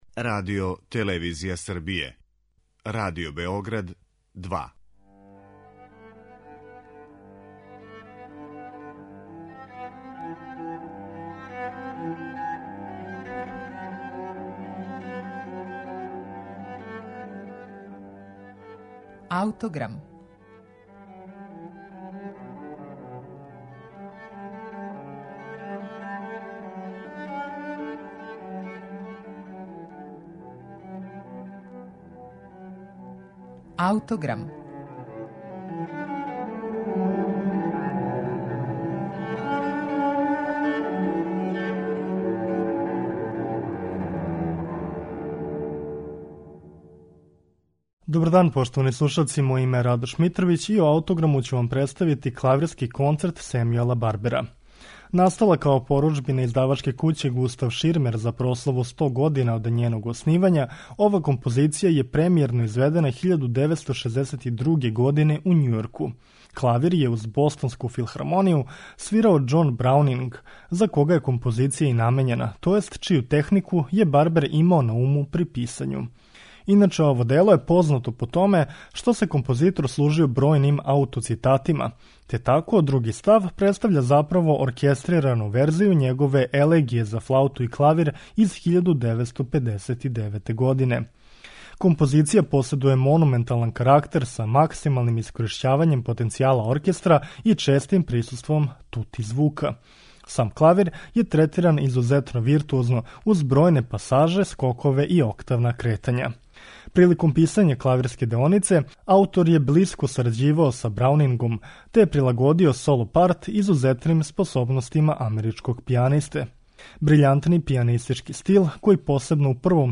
изузетно виртуозног остварења